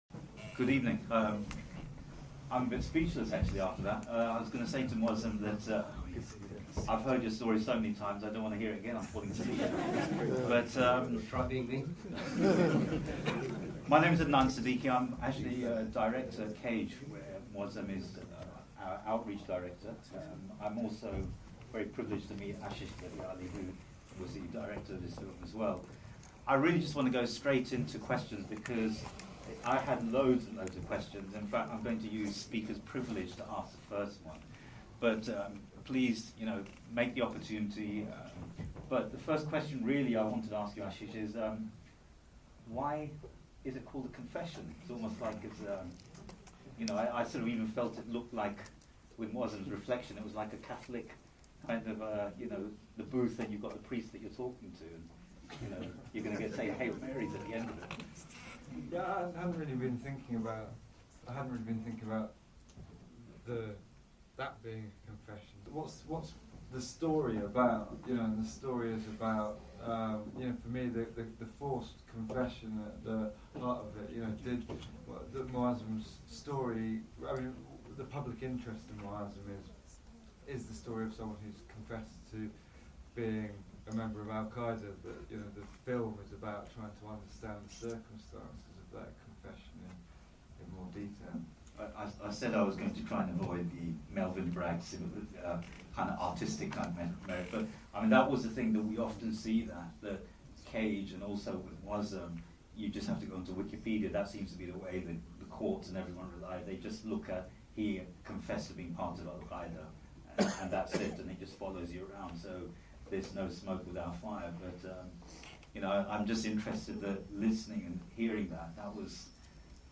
'The Confession' - Q&A with Moazzam Begg.mp3